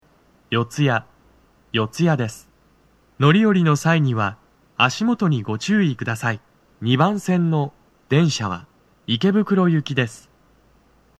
男声
到着放送1
TOA天井型()での収録です。